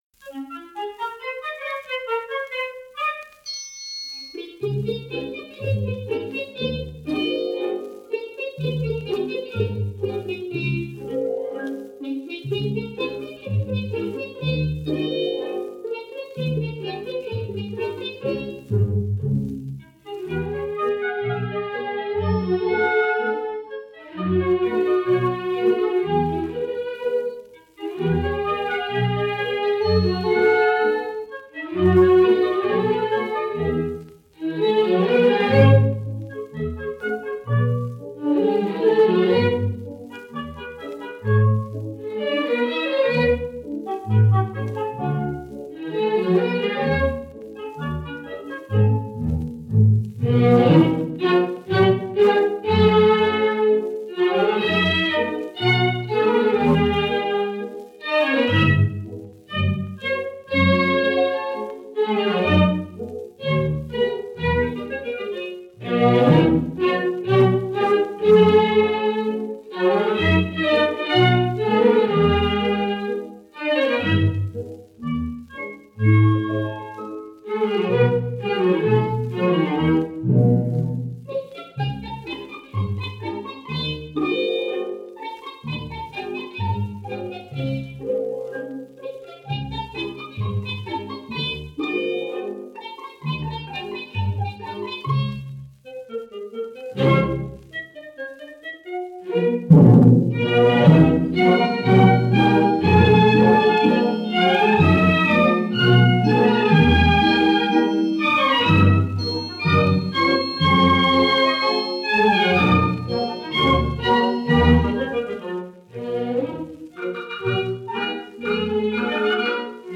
Schottisch.
78 rpm